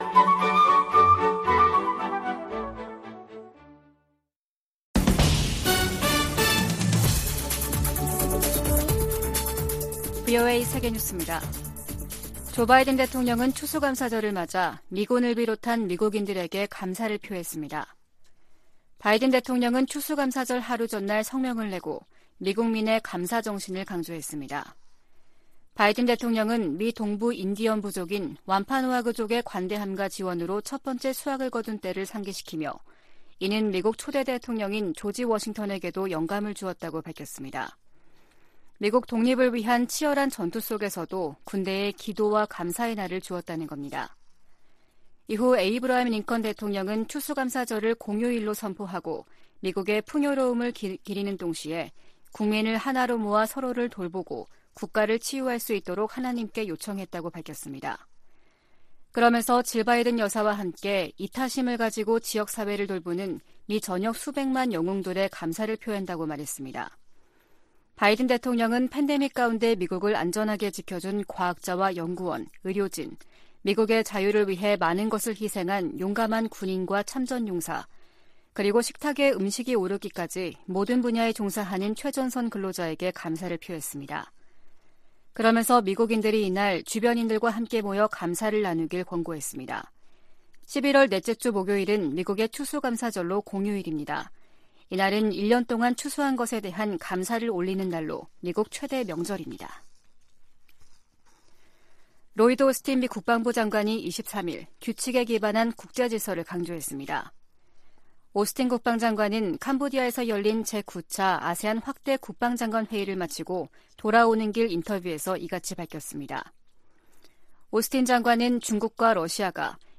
VOA 한국어 아침 뉴스 프로그램 '워싱턴 뉴스 광장' 2022년 11월 25일 방송입니다. 미 국무부는 미한 연합훈련에 대한 북한의 비난을 일축하며 북한이 방어적인 훈련을 구실로 불법 행동을 이어가고 있다고 지적했습니다. 한국 정부는 북한 김여정 노동당 부부장의 대남 비난담화에 도를 넘었다며 강한 유감을 표명했습니다.